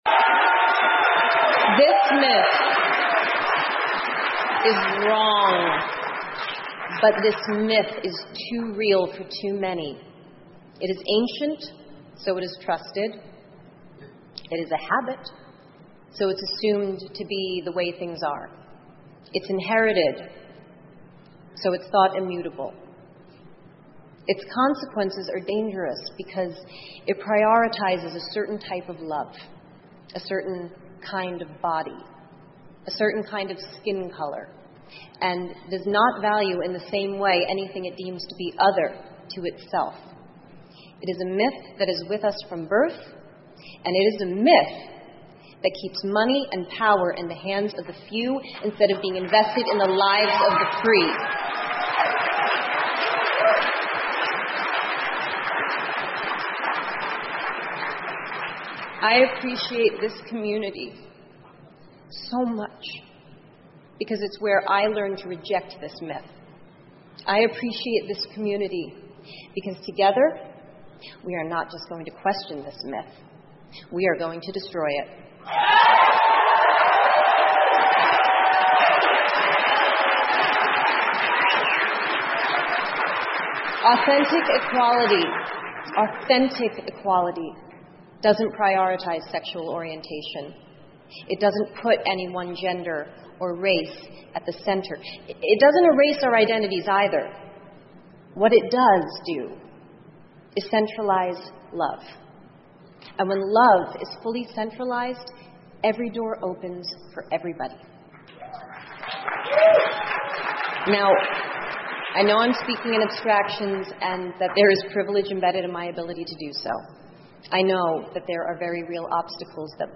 英文演讲录 安妮·海瑟薇：力挺同性恋者(2) 听力文件下载—在线英语听力室